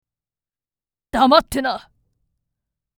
厳しめな口調と深い眉間のしわで、あまり評判はよくない。
SampleVoice02